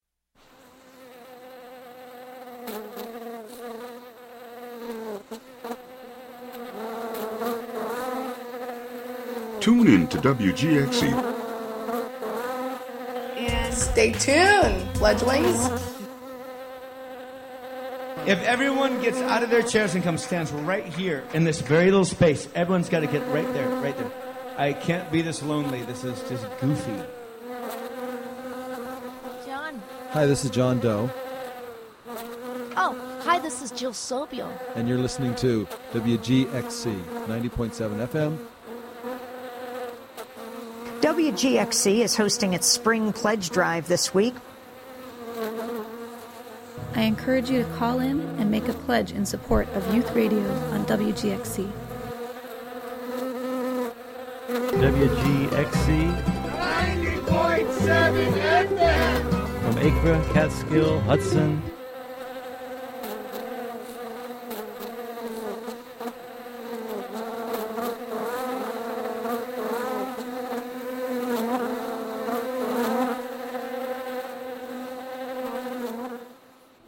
Bee Your Media, WGXC Spring Pledge Drive PSA bee collage (Audio)
Lots of bees. Some talk.